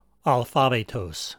alphabētos